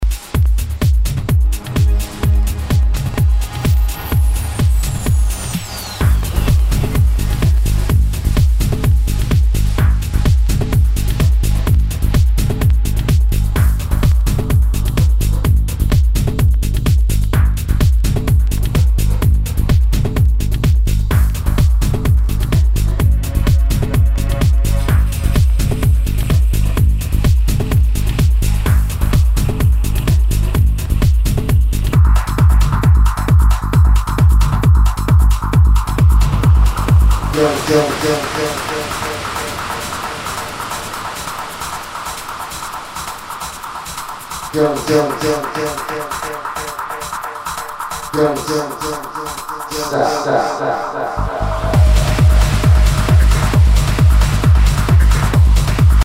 HOUSE/TECHNO/ELECTRO
ナイス！プログレッシブ・ハウス！